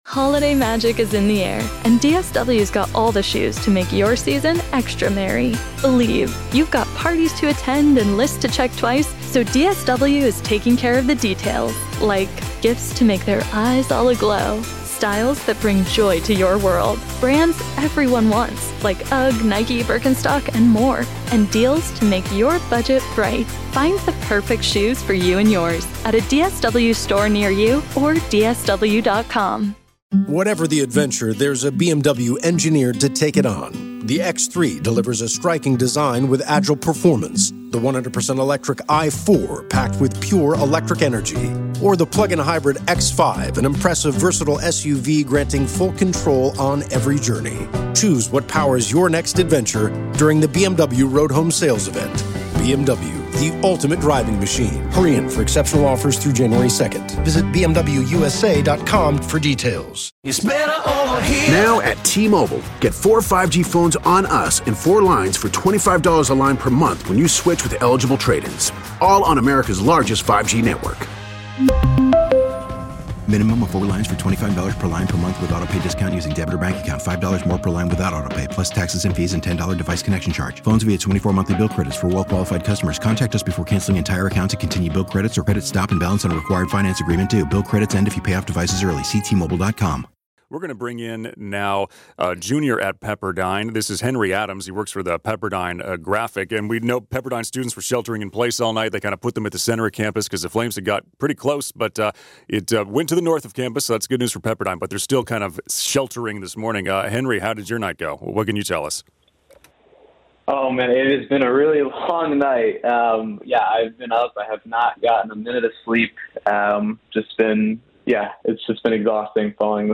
As the Franklin Fire raged through Malibu early Tuesday morning, Pepperdine University officials announced a shelter-in-place order was issued and urged students on campus to go to the Payson Library and the Tyler Campus Center.